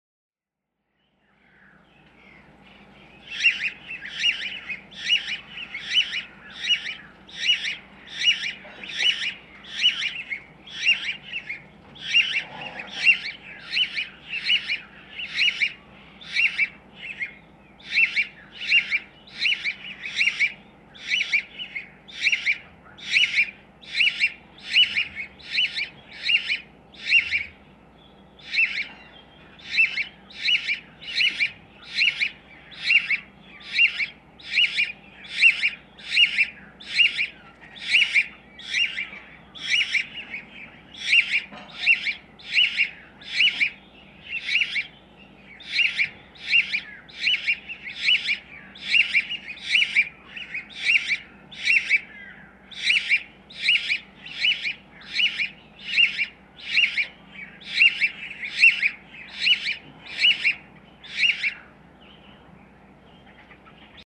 House sparrow
♫364. A portion of the above (♫363) slowed to half speed, revealing to our ears that all songs are the same. (1:03)
364_House_Sparrow.mp3